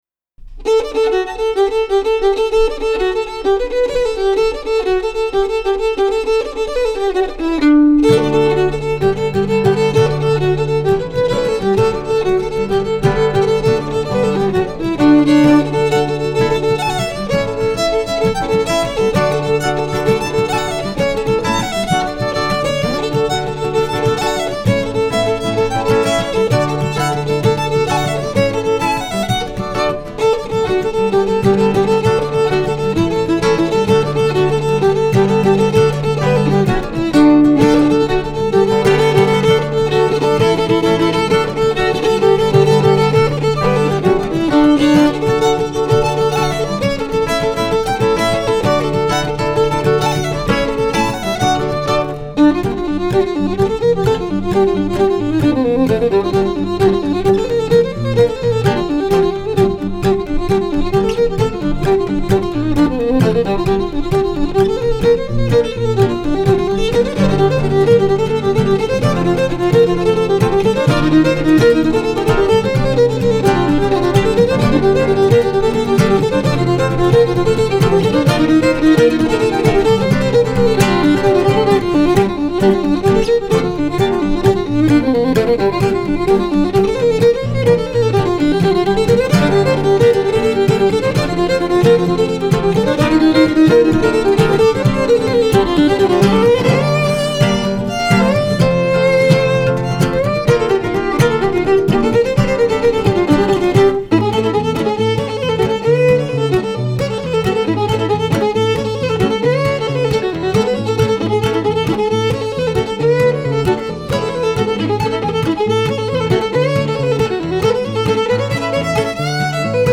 String Musicians
Live Fiddle
LiveFiddle.mp3